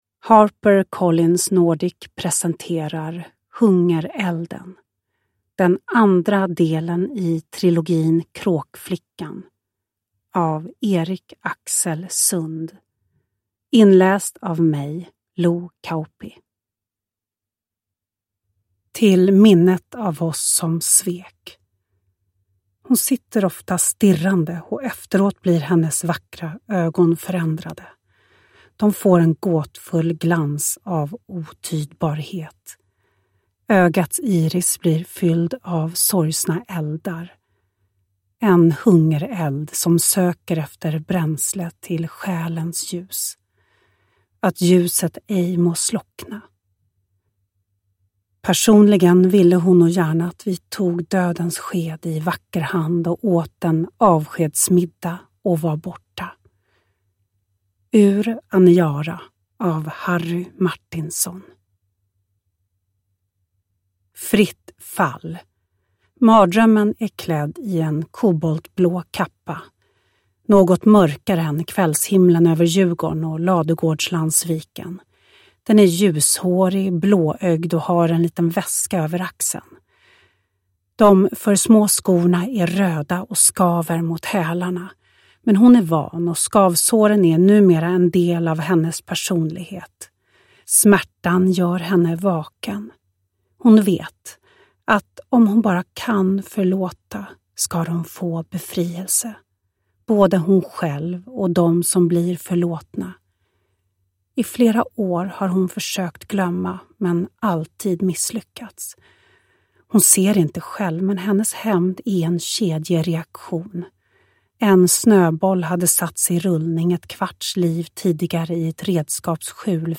Uppläsare: Lo Kauppi
Ljudbok